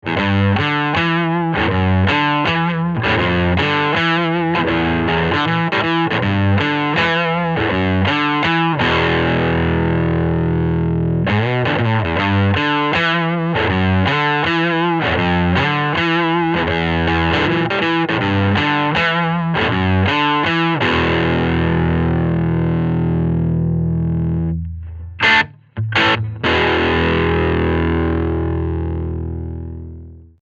• Les Paul Standard with Voodoo Humbuckers on the bridge pickup
The amp was set to clean.
Example 1: Tube Drive  amp
This is a chunky tube sound. It’s the closest sound to a cranked amp.
tube-drive.mp3